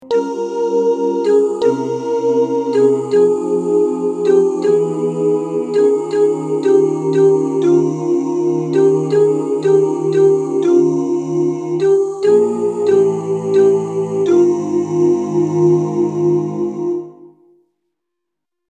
Key written in: F Minor
Type: Other mixed
Comments: Take this at a nice easy ballad tempo.